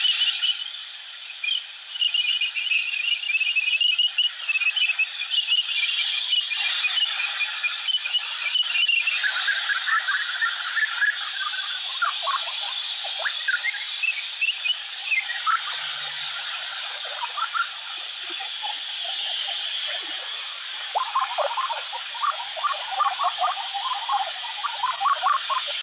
Grand Rhinolophe (Rhinolophus ferrumequinum) Quelque part à Culles les Roches. Septembre 2015.
Cette structure nasale complexe permet en effet aux rhinolophes d'émettre des ultrasons par les narines, à la différence de la plupart des autres espèces de chauves-souris européennes qui émettent par la bouche.
signaux du Grand Rhinolophe (fréquence entre 78 et 85 kHz) sont peu puissants mais restent audibles au détecteur d'ultrasons jusqu'à près de 10 mètres.
Grand-rhinolophe.mp3